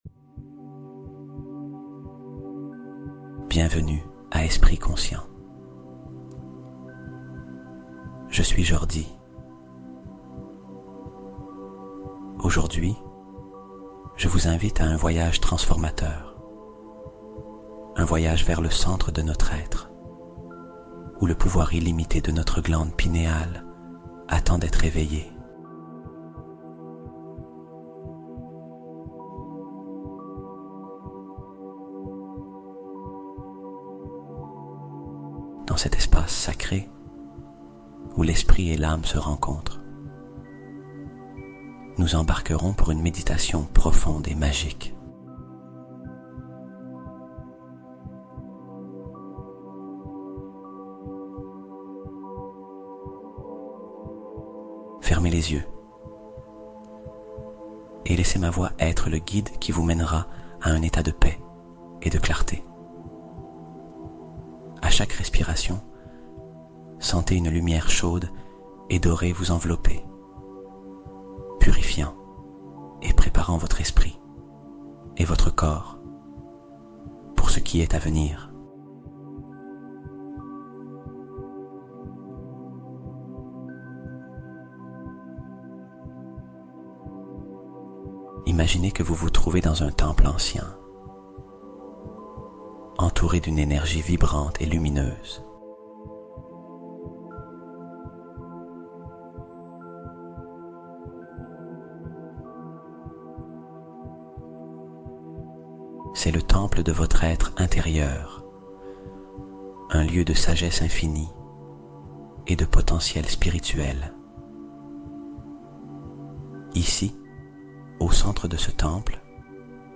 Débloque Ta Glande Pinéale en 20 Minutes : Clarté Spirituelle et DMT Naturelle Activée | 963Hz